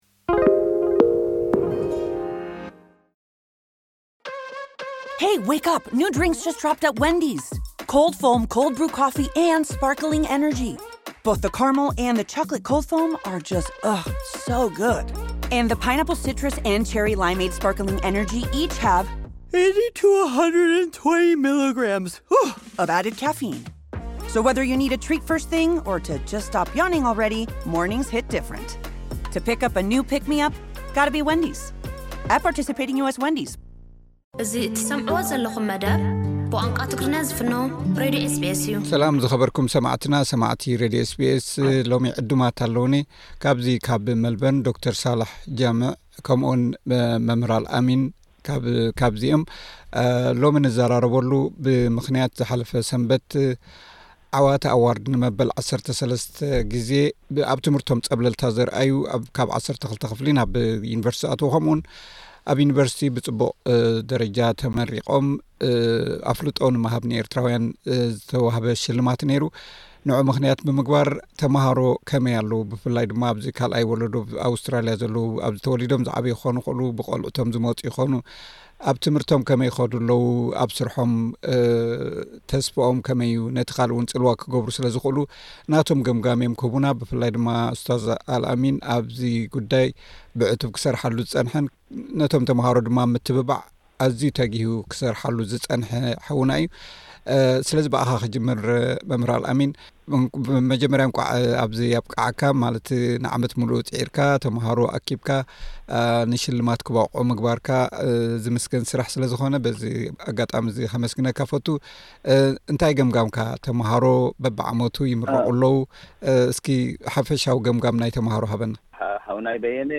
ዝገበርናዮ ዕላል።